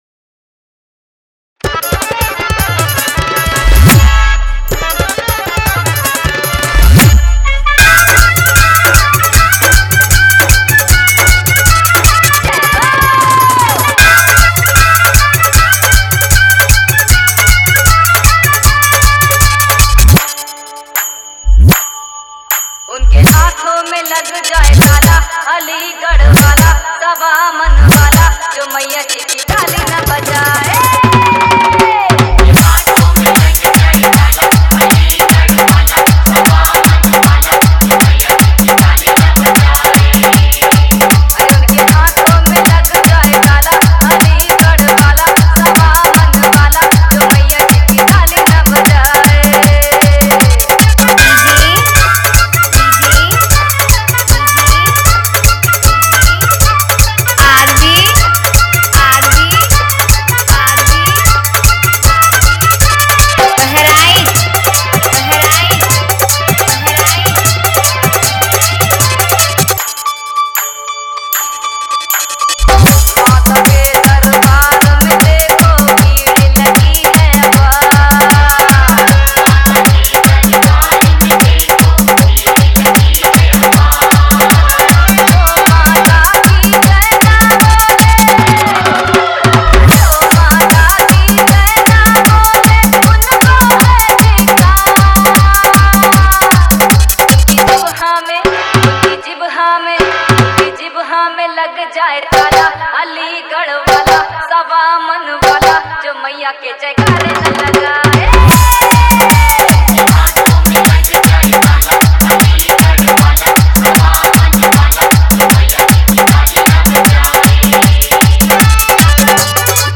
Navratri Song